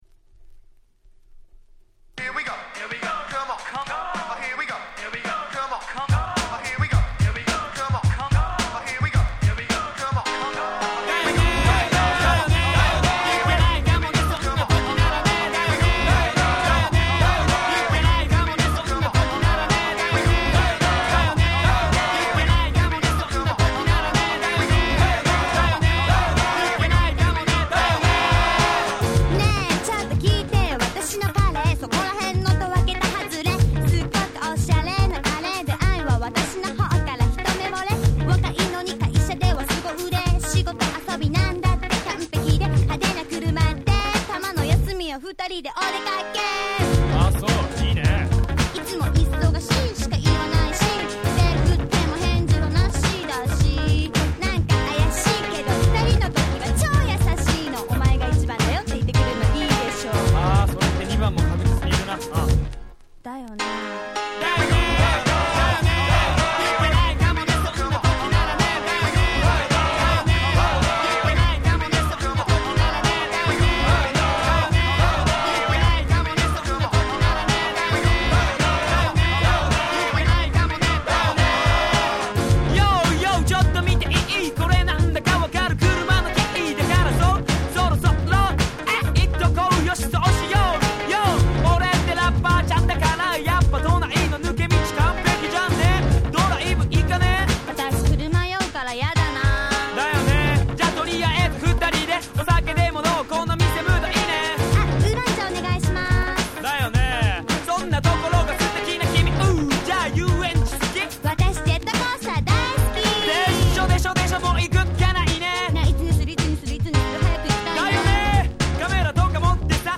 95' J-Rap Classic !!